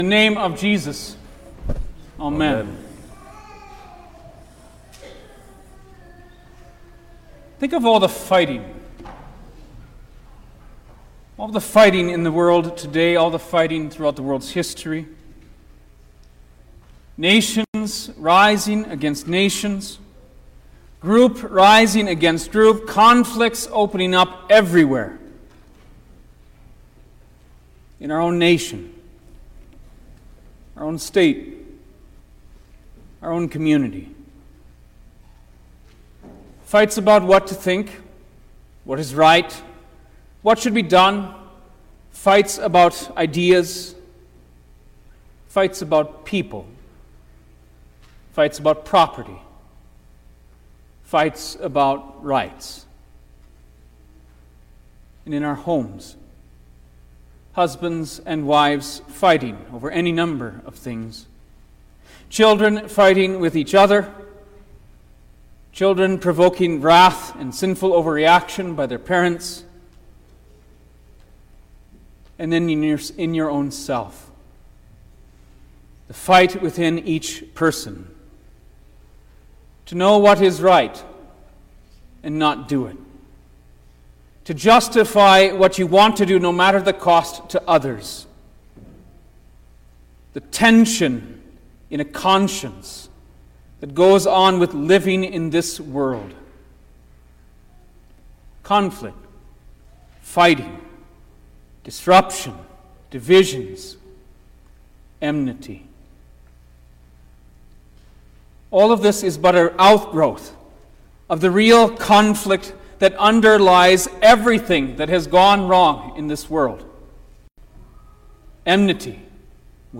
April-7_2023_Good-Friday_Sermon-Stereo.mp3